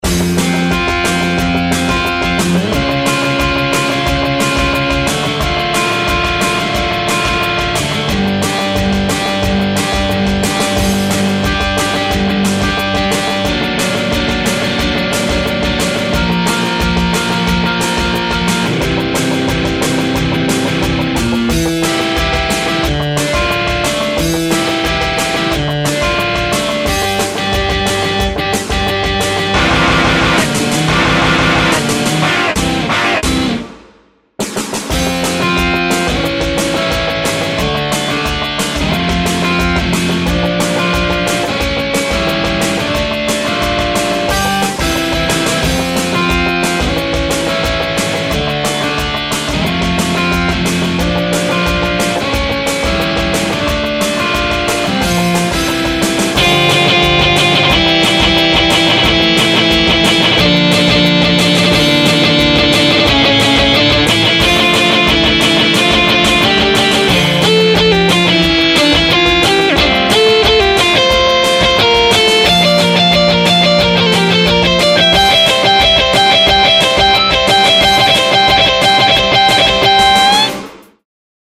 ●CleanStrat●AboveTheRim●Chunk2